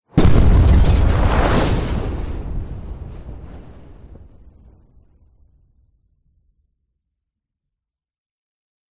На этой странице собраны звуки ударной волны после взрыва — от глухих ударов до резких перепадов давления.
Звук разбитого стекла и ударной волны